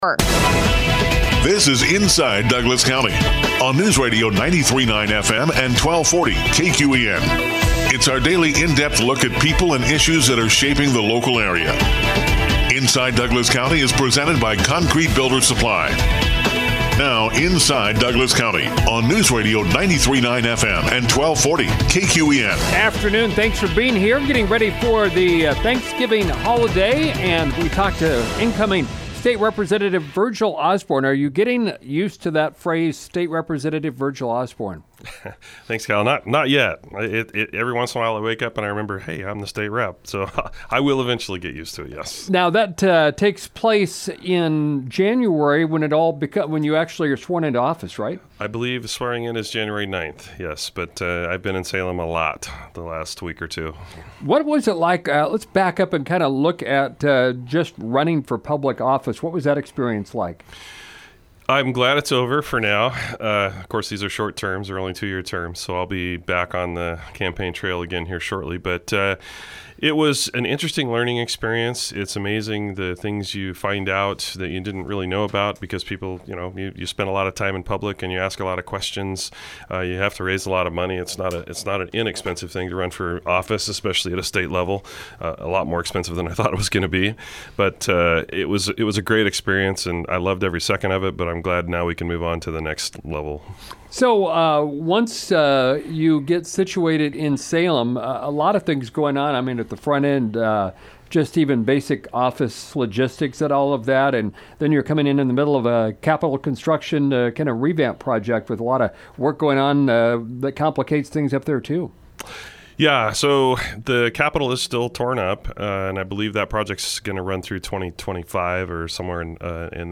Incoming State Representative Virgle Osborne talks about his plans for being a member of the Oregon State Legislature.